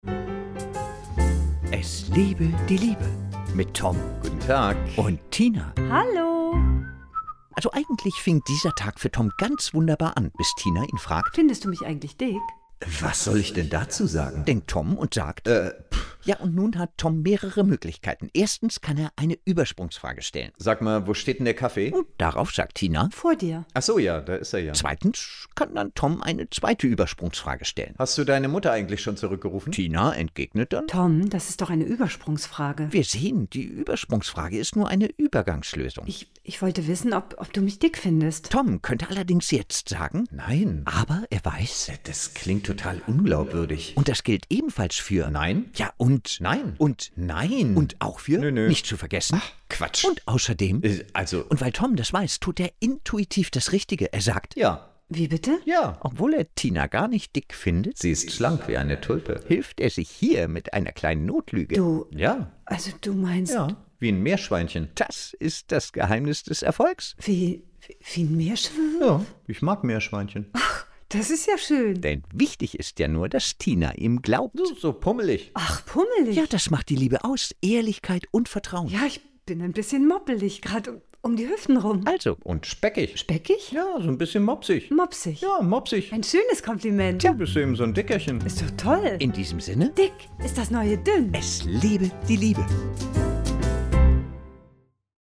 Hörfunkserie
Die Radio-Comedy bekannt vom WDR, NDR, SWR und HR.